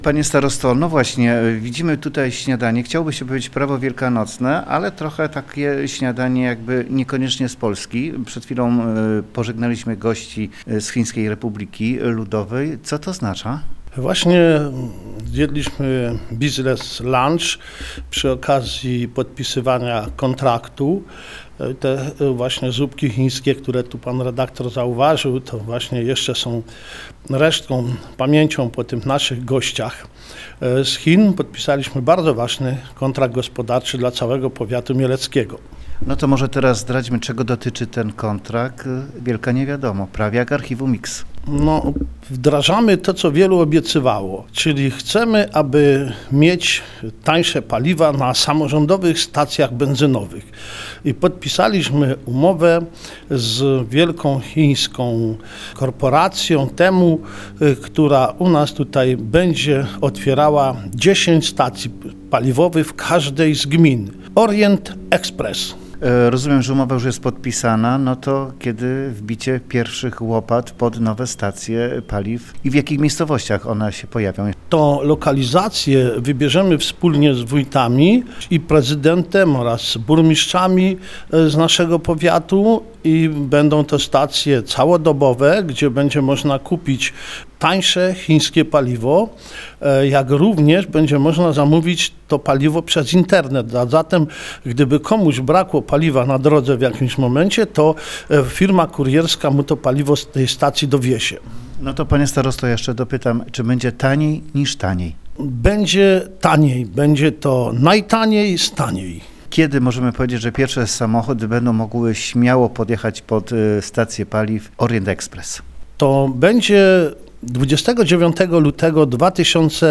O szczegółach tej współpracy mówi starosta mielecki Kazimierz Gacek.